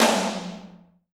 Royality free tom drum single hit tuned to the F note. Loudest frequency: 615Hz
• Hi Tom Drum Single Hit F Key 15.wav
hi-tom-drum-single-hit-f-key-15-Ni1.wav